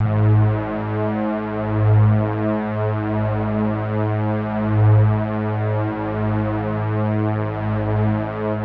FLANGE MOOG.wav